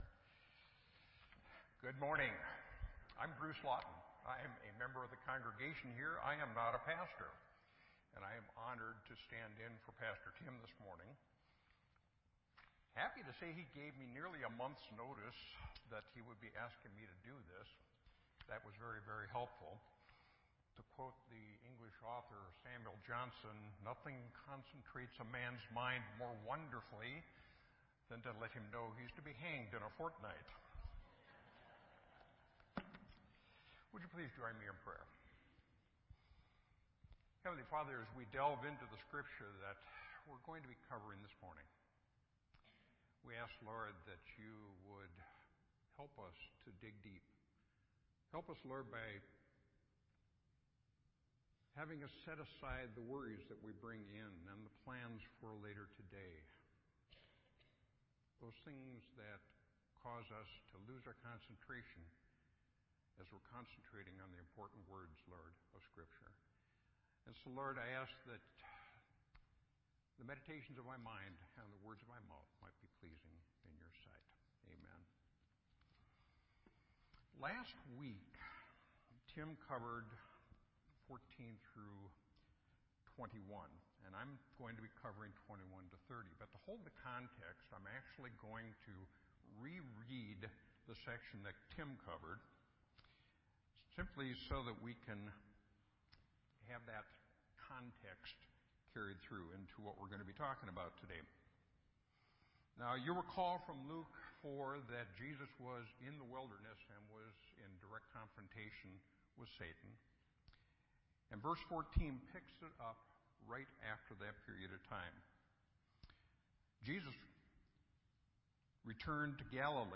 This entry was posted in Sermon Audio on February 4